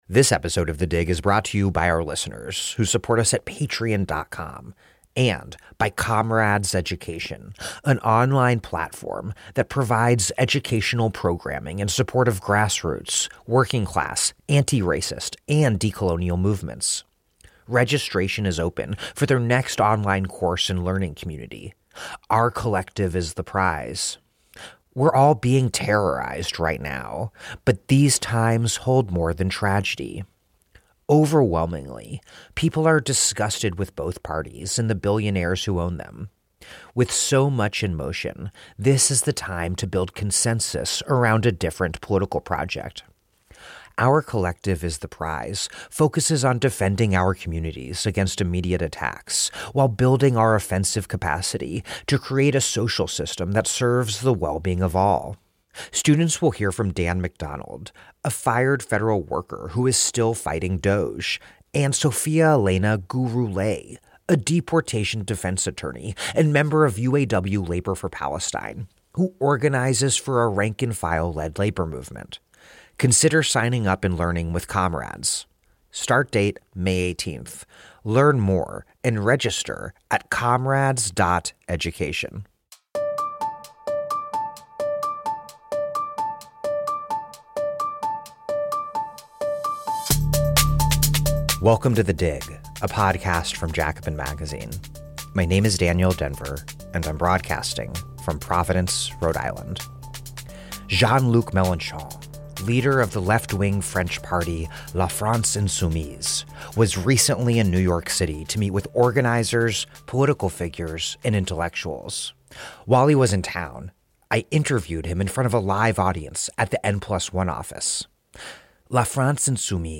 Voice translation